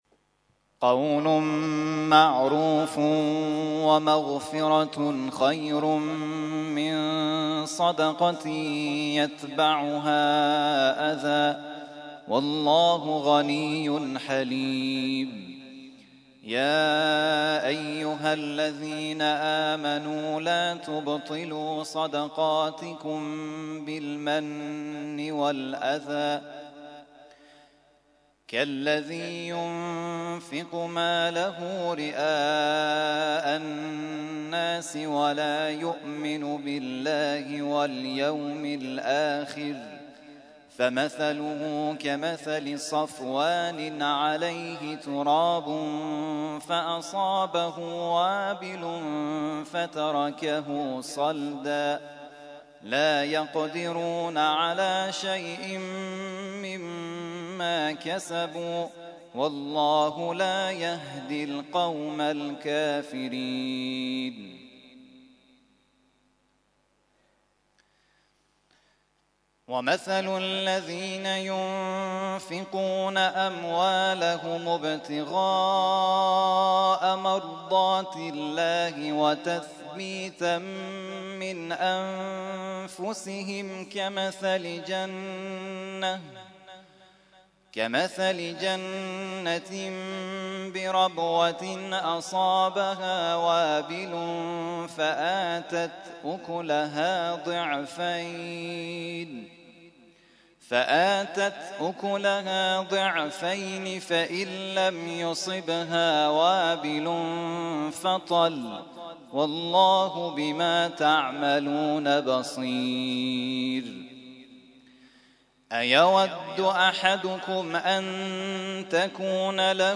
ترتیل خوانی جزء ۳ قرآن کریم در سال ۱۳۹۱